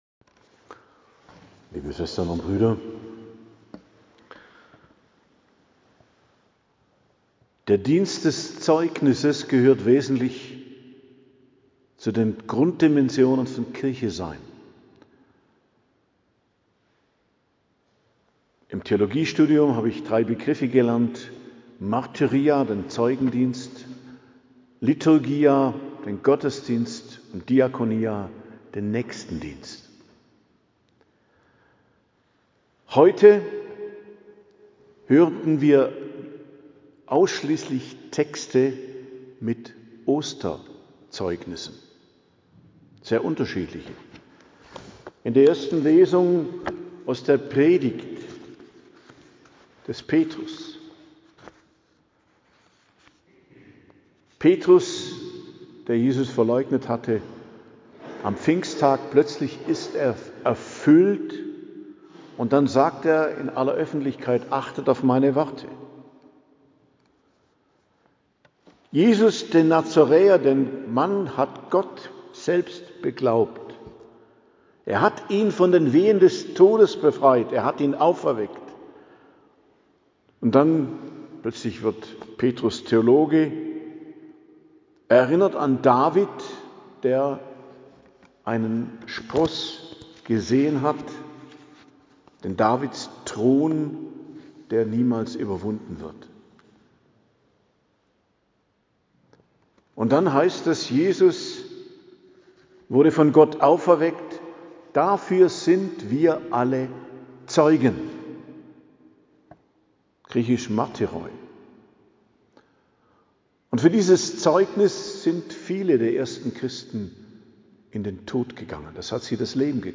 Predigt am Ostermontag, 6.04.2026 ~ Geistliches Zentrum Kloster Heiligkreuztal Podcast